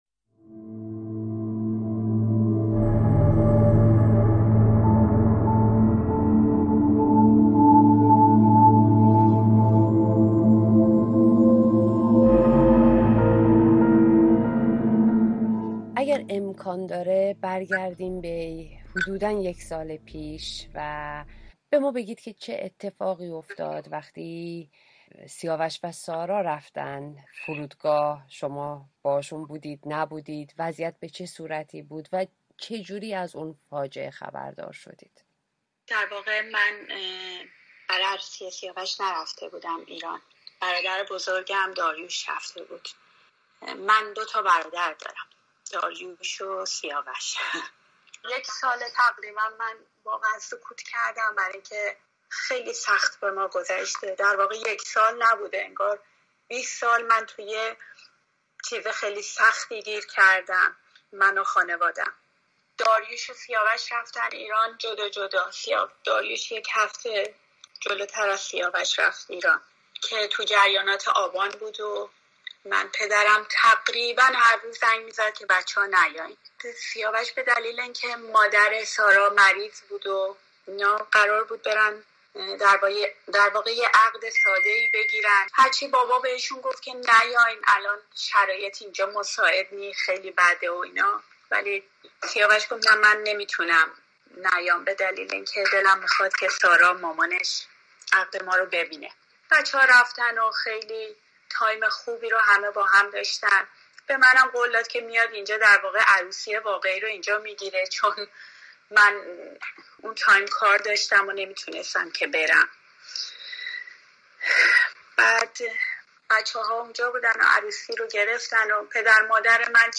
پرواز ۷۵۲؛ گفت‌وگو